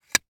household
Plastic Tent Poles Connecting 2